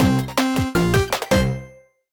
shard_get.ogg